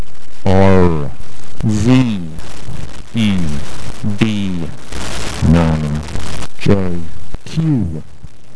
Dialogo tra due psicologi del lavoro — ComplexLab